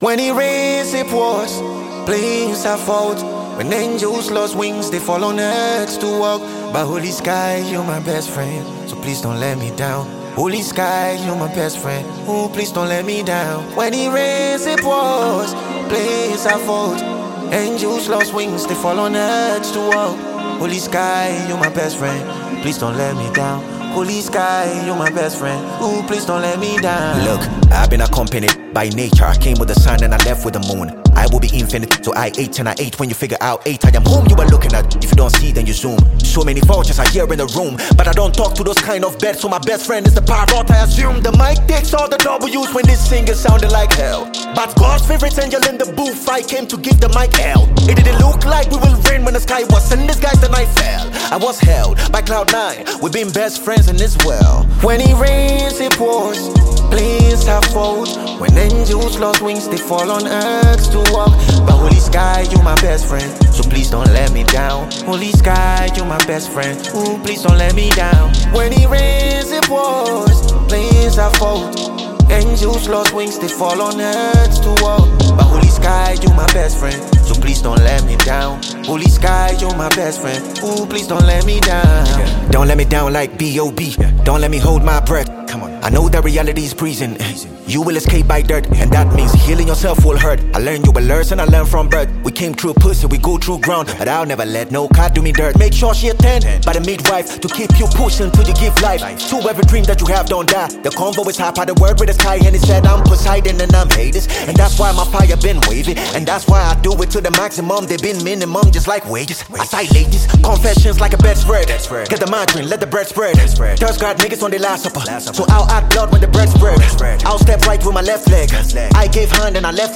hard-hitting and introspective track